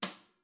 VSCO 1打击乐资料库 鼓 " 小鼓（小鼓3 rimshot f 1
Tag: 边敲击 打击乐器 小鼓 VSCO-2 单票据 多重采样